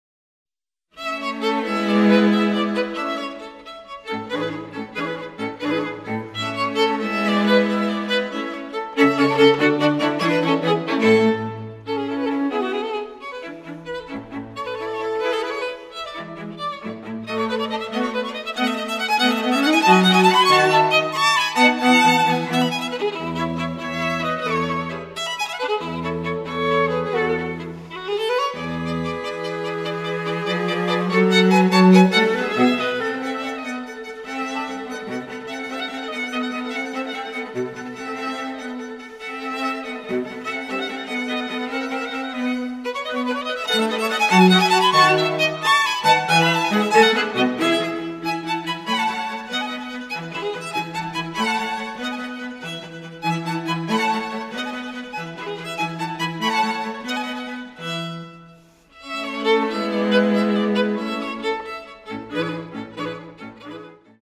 first violin
second violin
viola
cello